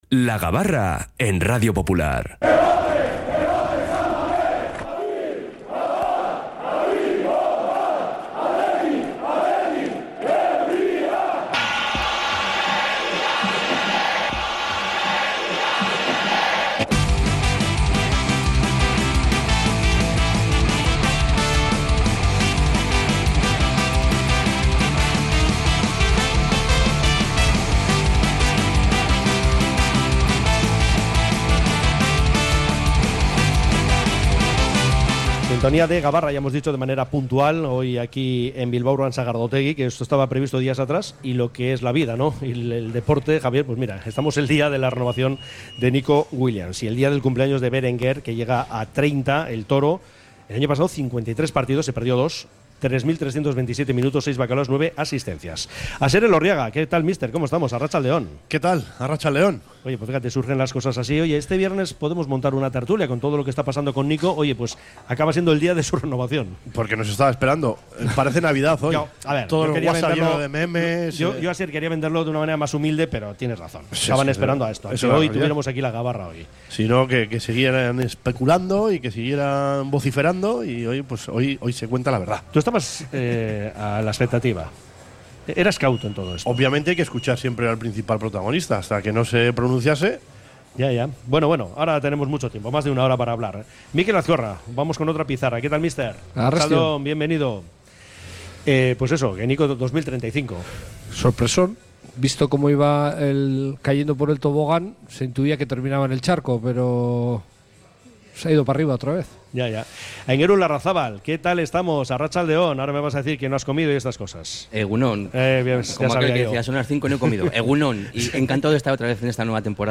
Hemos analizado en nuestra tertulia la extensión del contrato de Nico con el Athletic por ocho temporadas más, con aumento de su cláusula